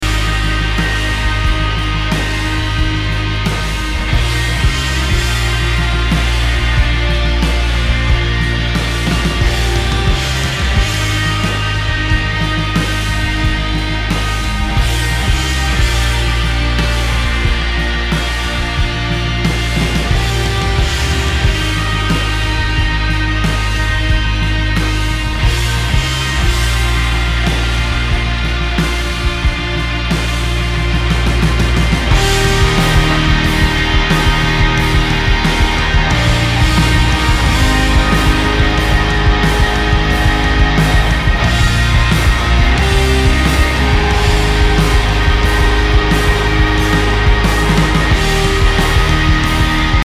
Post Rock, Experimental Rock >